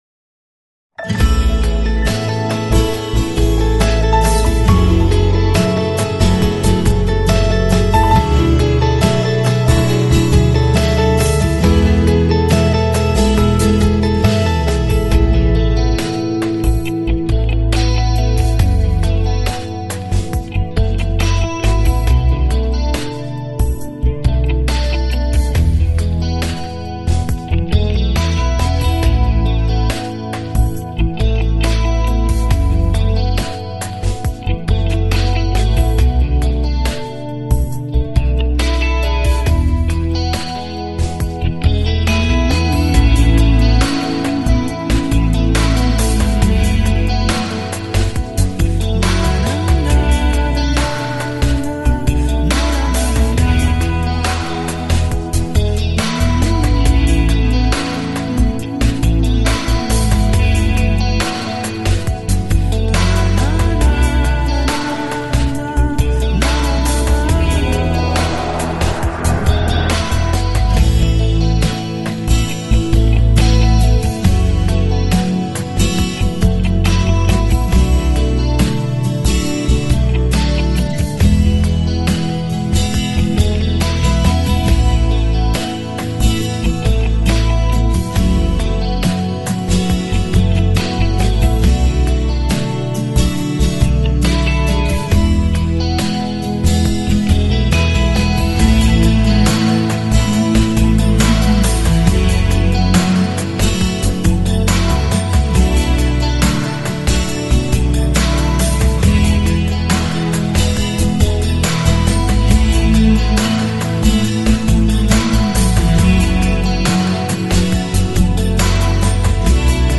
伴奏：